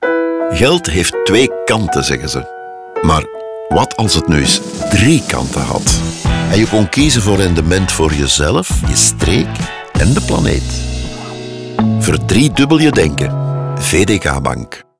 Les spots radio ont été interprétés par Jan De Smet (par le passé, membre de De Nieuwe Snaar) et sont soutenus musicalement par la chanson Fifteen Floors du groupe belge Balthazar.
VDK Bank RADIO 15s.wav